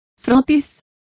Complete with pronunciation of the translation of swab.